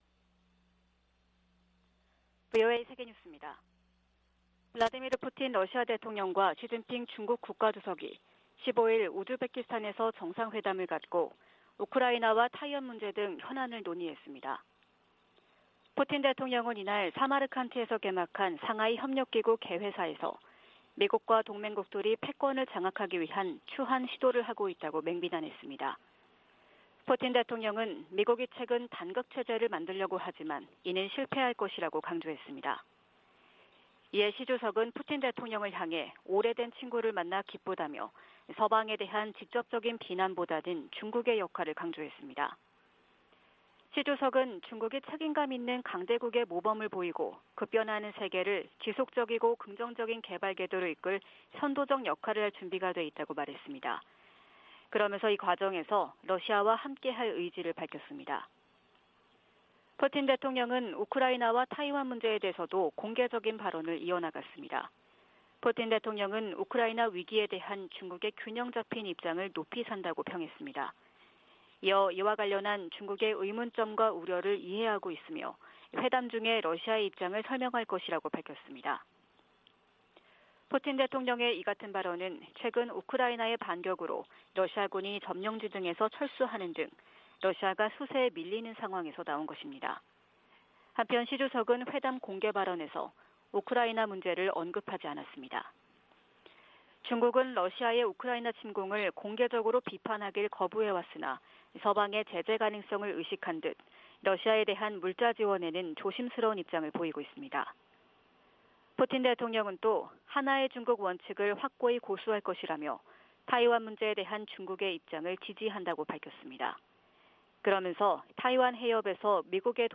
VOA 한국어 '출발 뉴스 쇼', 2022년 9월 16일 방송입니다. 미 국방부는 북한의 핵무력 정책 법제화와 관련해 동맹의 안전 보장을 위해 모든 조치를 취할 것이라고 밝혔습니다. 미 국제기구대표부는 북한의 핵무력 법제화에 우려를 표명하며 북한은 결코 핵무기 보유국 지위를 얻을 수 없을 것이라고 강조했습니다. 미 상원의원들이 대북 압박과 억지를 유지하고 대북특별대표직을 신설할 것 등을 요구하는 법안을 발의했습니다.